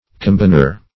Combiner \Com*bin"er\, n. One who, or that which, combines.